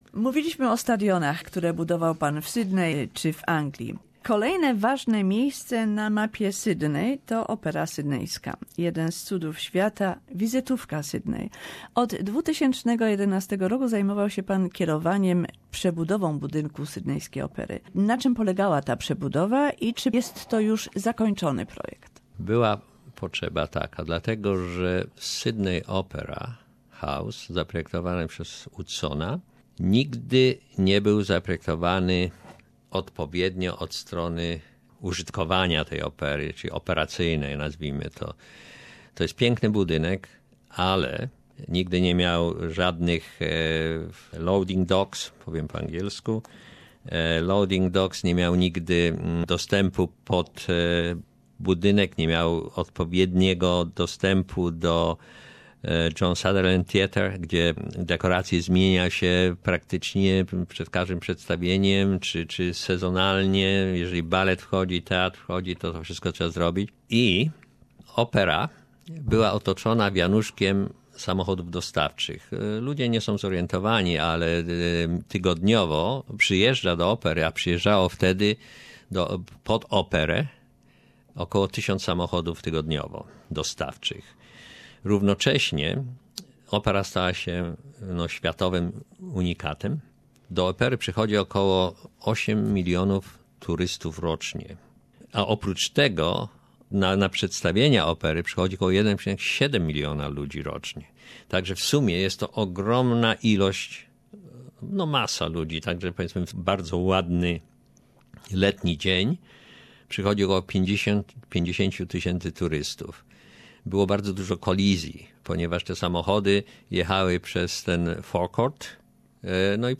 Part 3 conversation